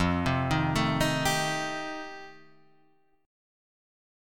F9 chord